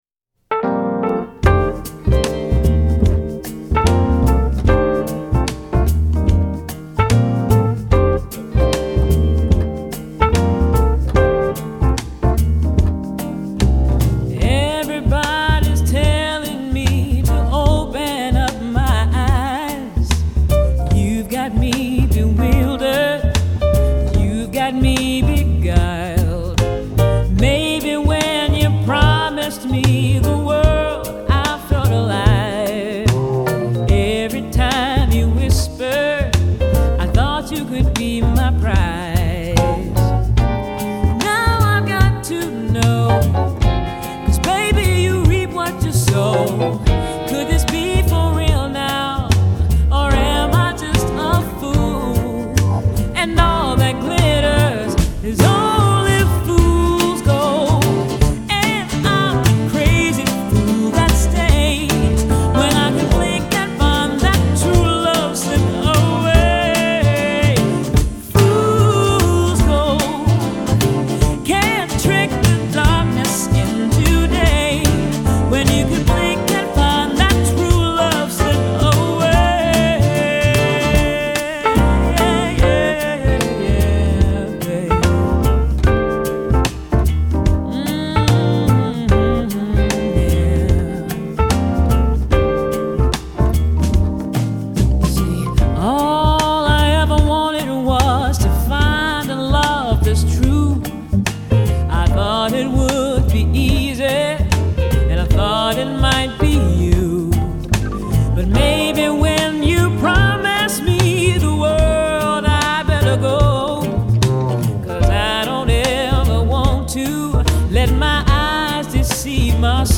Pretty easy on the ears.